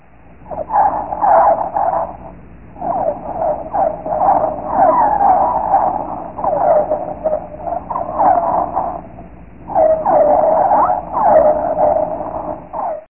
Som de Baleia
Baleia.mp3